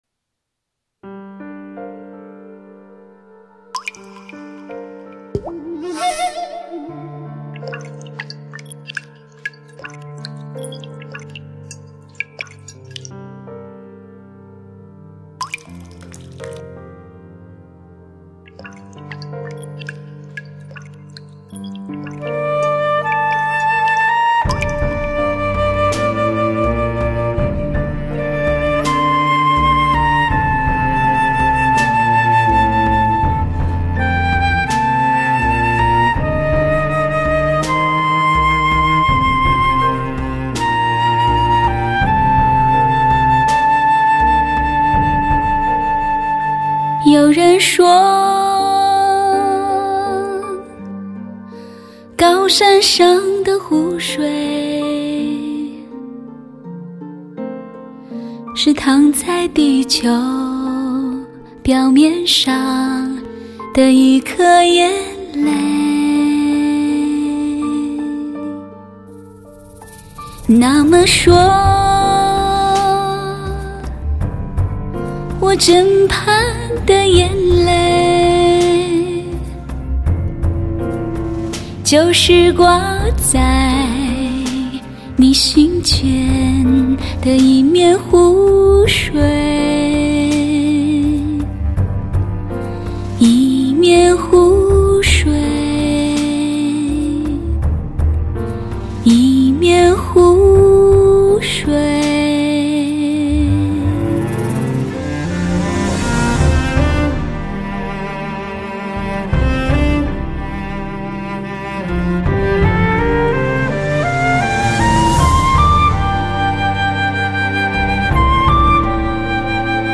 采用最新美国DTS-ES 6.1顶级编码，创造超乎想象完美环绕声震撼体验。
高端特制紫水晶CD，音色更加圆润细腻，声场更加雄壮宽广。
柔和而动听的旋律让您仿佛行驶在充满着鲜花与阳光的幸福之路。轻松而愉悦的曲子解除旅途中的疲惫……